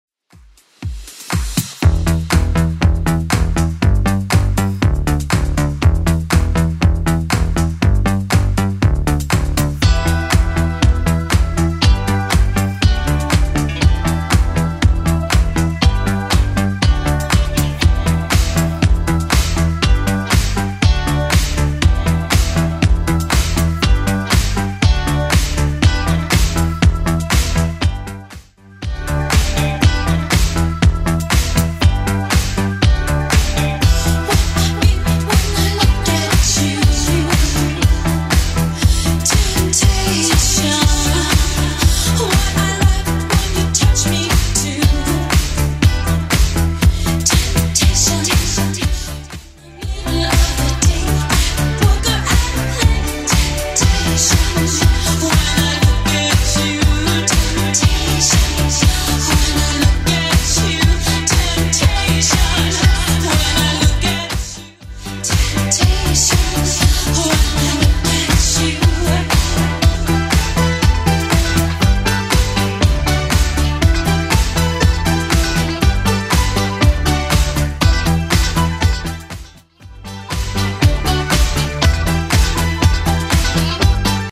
Genre: TOP40
BPM: 125